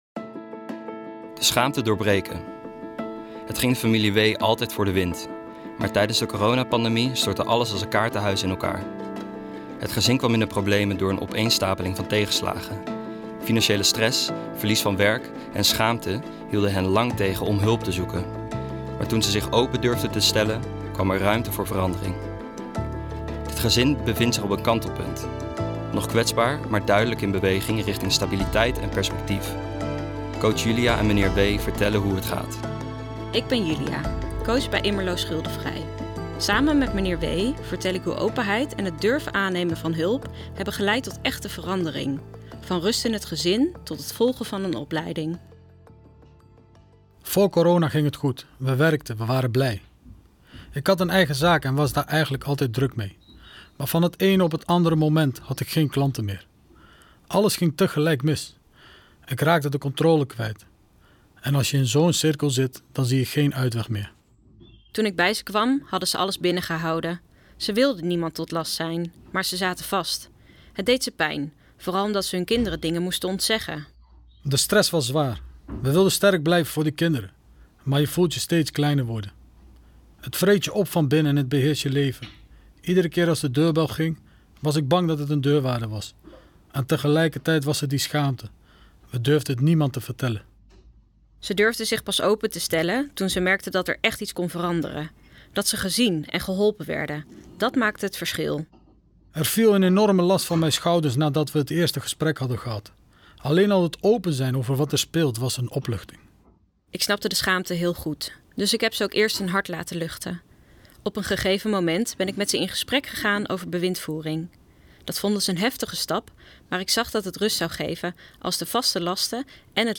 De verhalen worden verteld door stemacteurs.